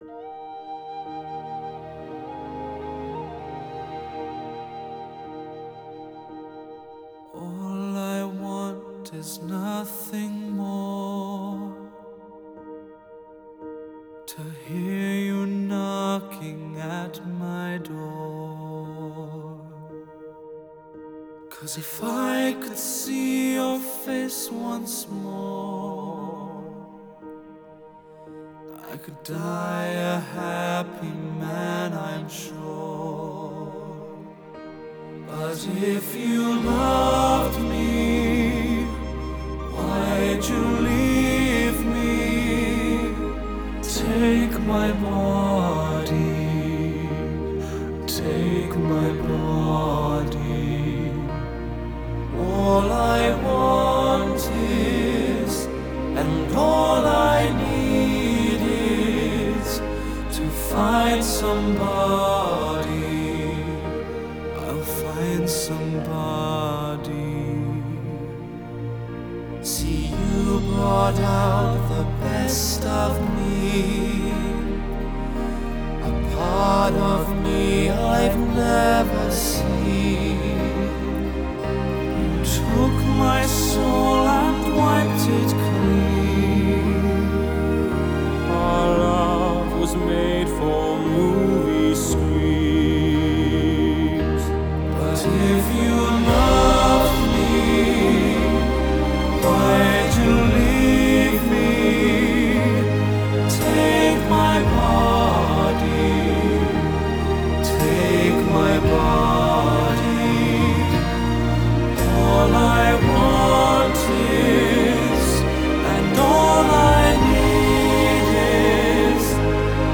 Genre: Vocal, Pop, Classical